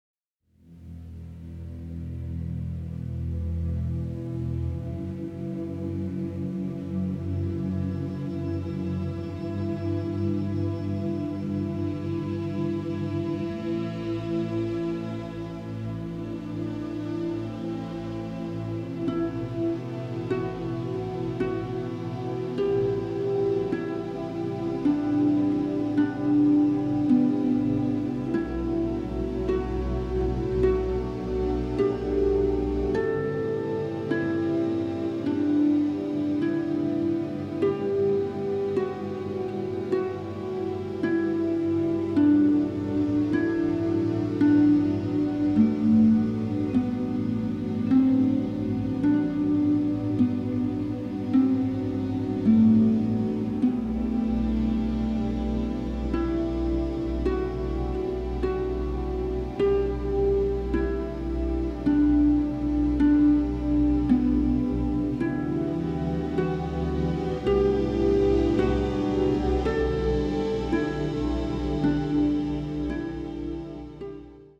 colorful orchestral tapestry is a true exotic delight.